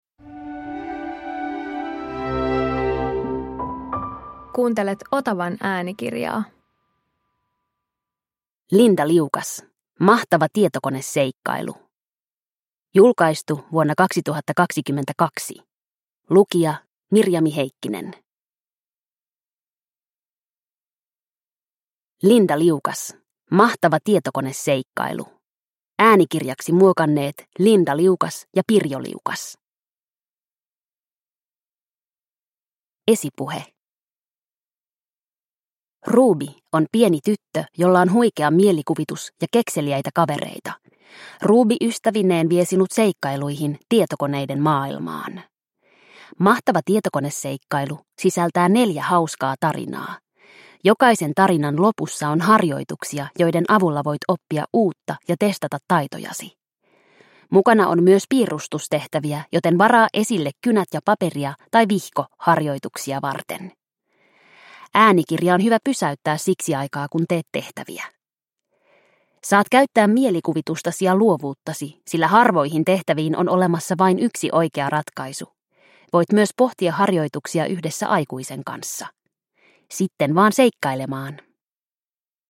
Hello Ruby - Mahtava tietokoneseikkailu – Ljudbok – Laddas ner